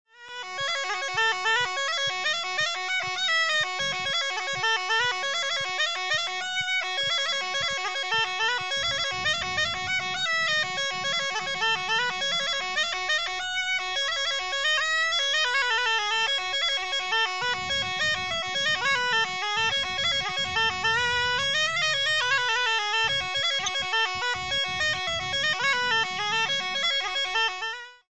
Cabrettes et Cabrettaïres le site Internet officiel de l'association de musique traditionnelle auvergnate
Les bourrées